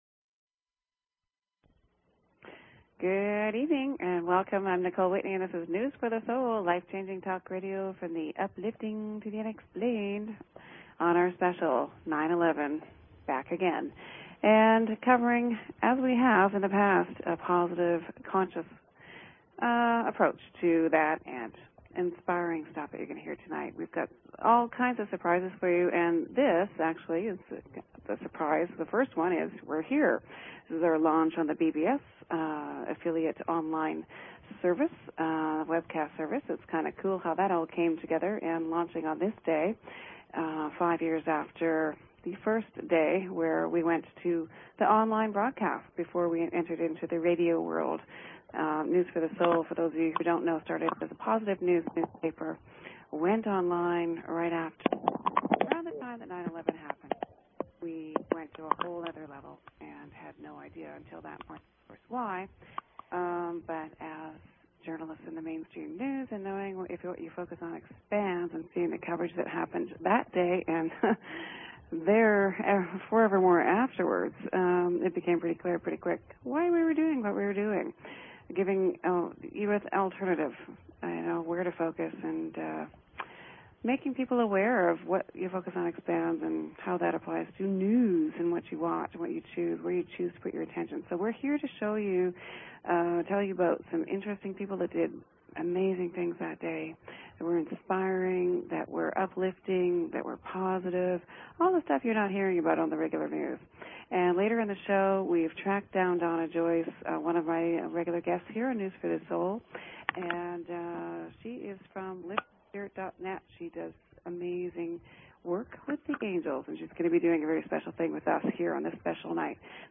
Talk Show Episode, Audio Podcast, News_for_the_Soul and Courtesy of BBS Radio on , show guests , about , categorized as
On this 9 11 we invite you to turn off the 'horror of 9 11 coverage' and tune in here 9pm PST for something that will make you feel better. Tonight we launch on our most recently added webcast affiliate BBS RADIO (live webcast with call in lines!) LOVE ON 9~11: Alternative media with an empowering positive point ~ 9pm PST On Sept 11th.